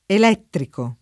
[ el $ ttriko ]